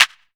• Clap Sample F Key 12.wav
Royality free clap one shot - kick tuned to the F note. Loudest frequency: 3016Hz
clap-sample-f-key-12-0xI.wav